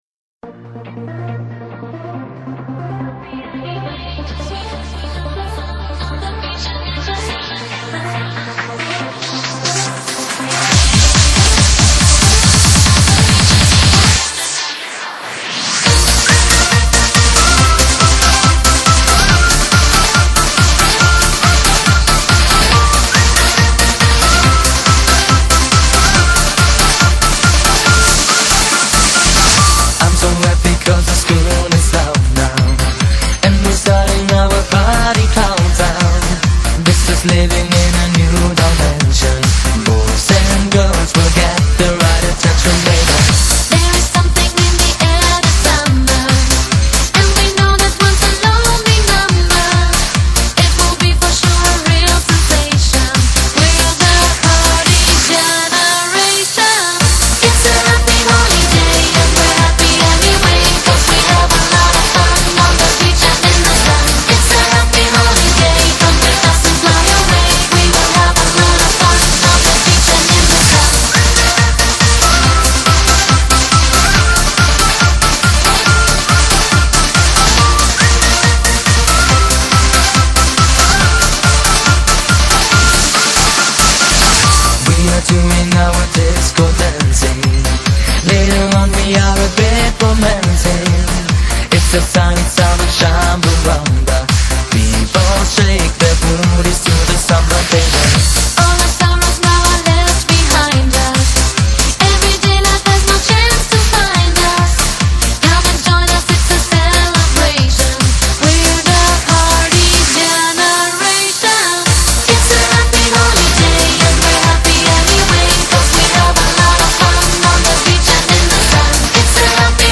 走的是纯流行舞曲路线